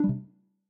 retroarch-assets/sounds/notice_back.ogg at master
notice_back.ogg